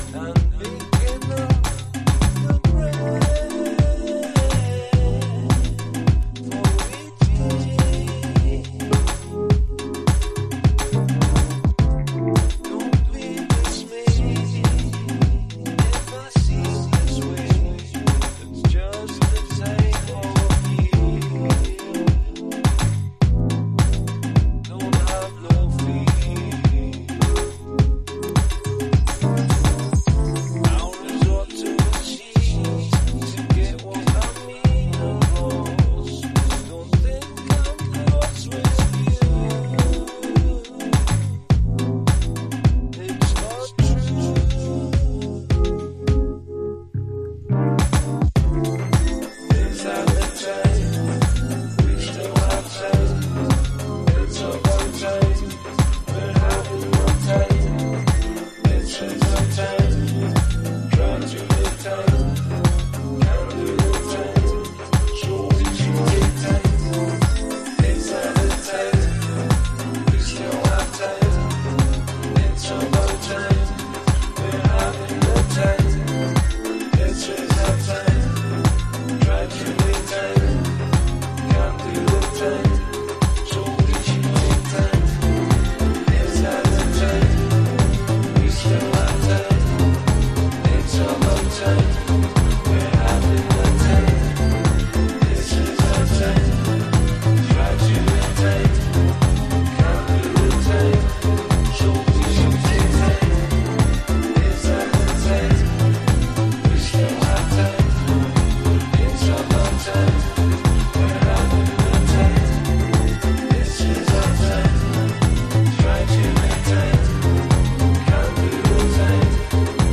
House / Techno
3/4ビートと鍵盤の嵐でグイグイ引き込む、UK産らしいポスト・デトロイトハウス。